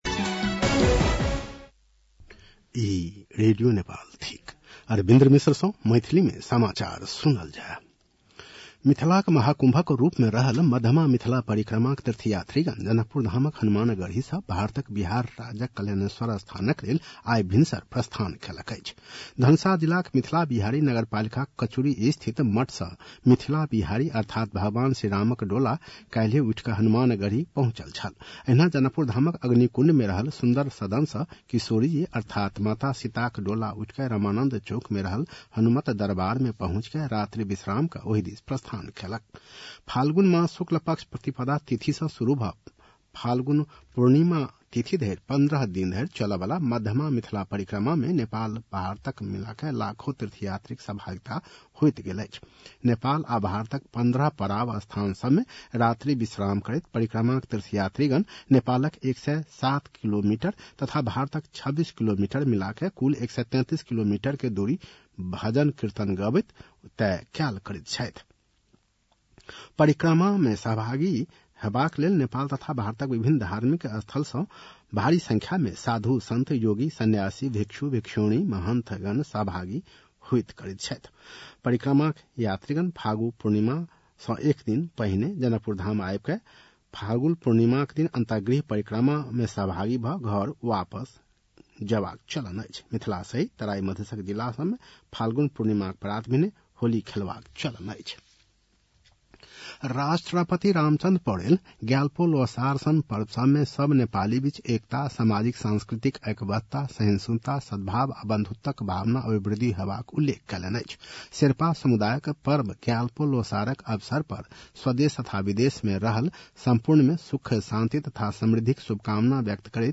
मैथिली भाषामा समाचार : १७ फागुन , २०८१
Maithali-news-11-16.mp3